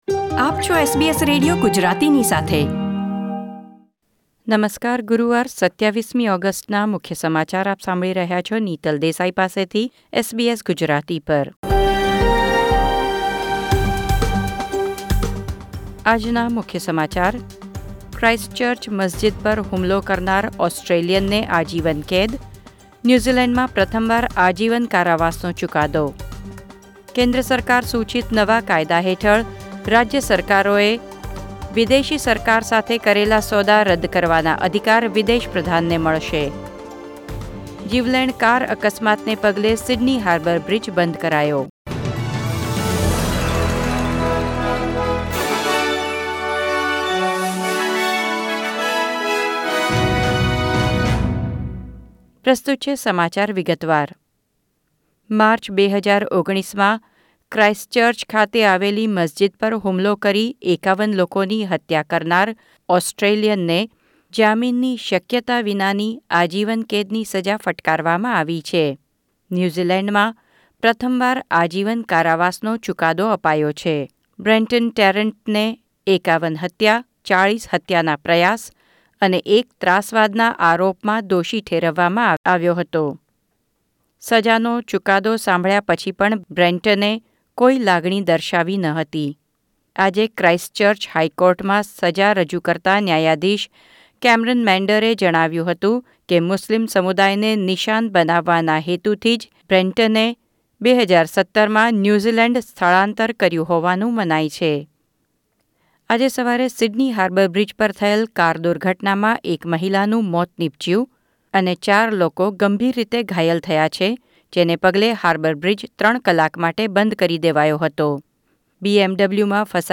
SBS Gujarati News Bulletin 27 August 2020